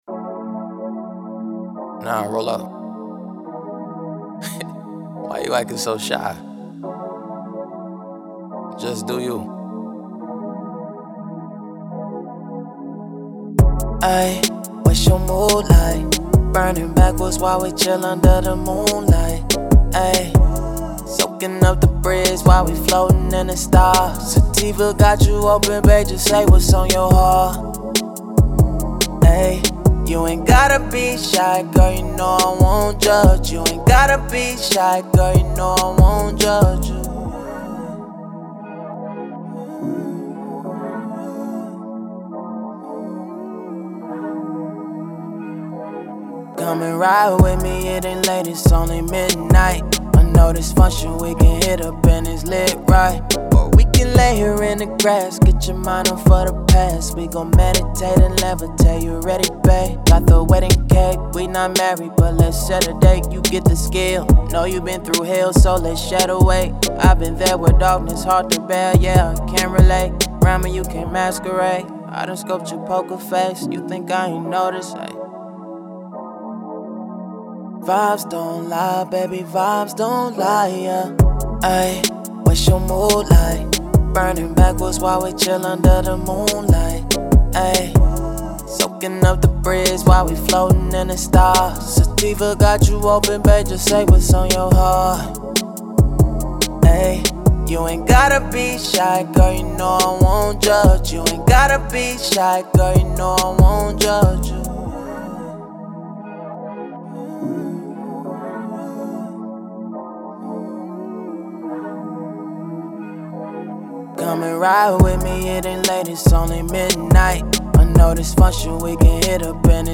R&B
C# minor